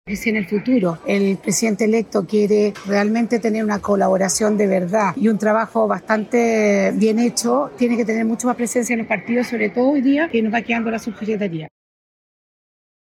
En esa línea, la diputada Ximena Ossandón (RN) planteó que más presencia de figuras militantes dará mejores resultados a futuro.